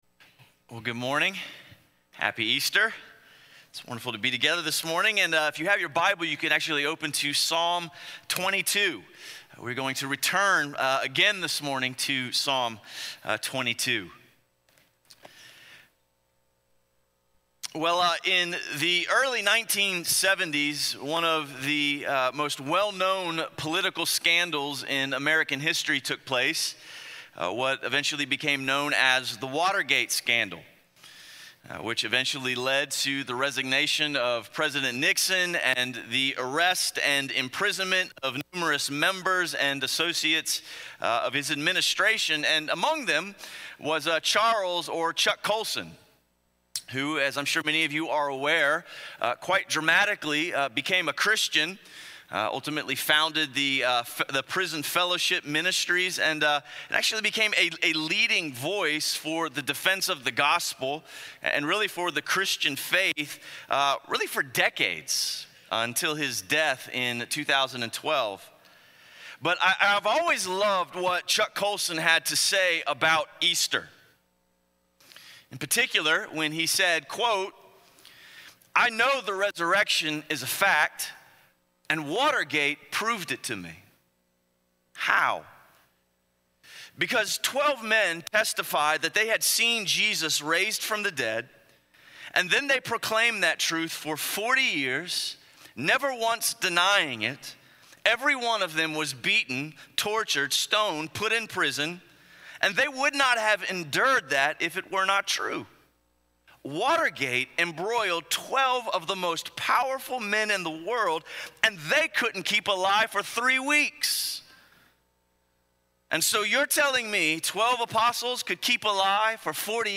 Easter Sunday – Stand Alone Sermons | Crossway Community Church